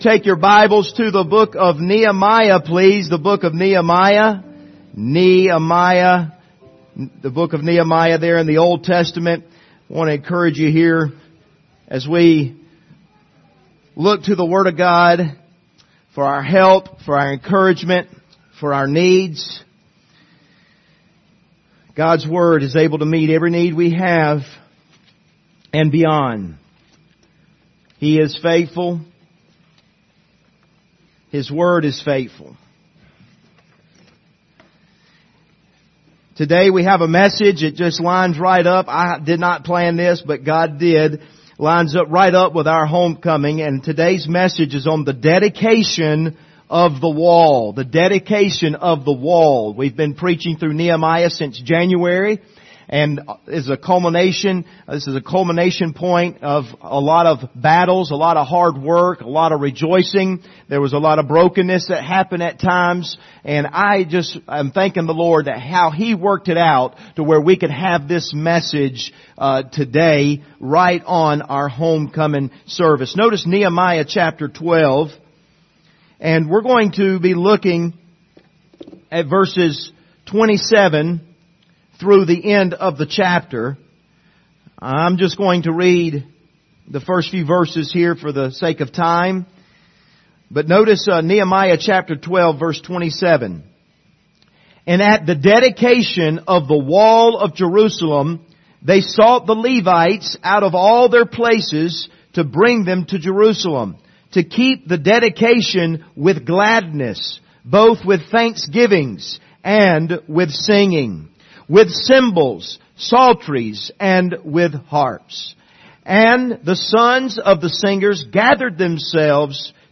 Passage: Nehemiah 12:27-47 Service Type: Sunday Morning